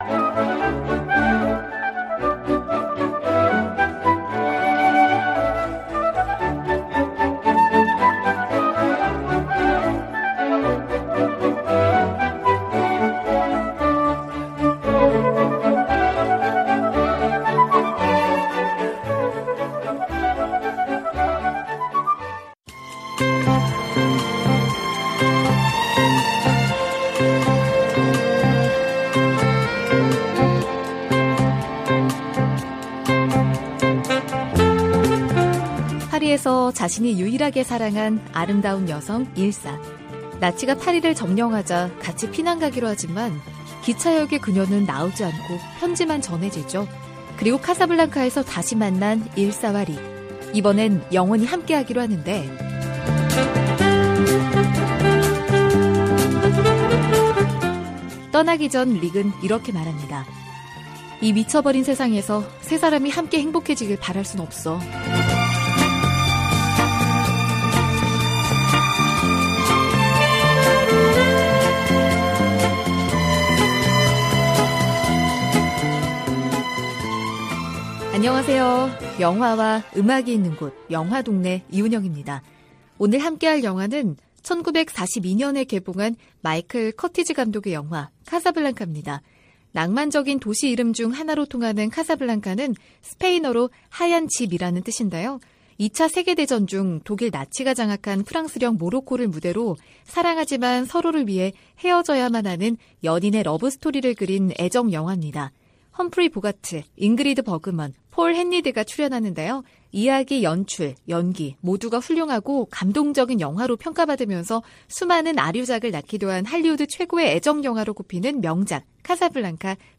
VOA 한국어 방송의 일요일 오전 프로그램 2부입니다.